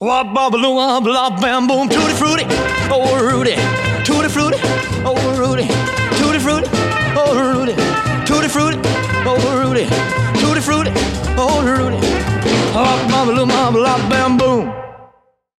À chaque livre, une ambiance musicale différente grâce à 7 extraits de chansons en version originale.
MES-CHANSONS-ROCK.mp3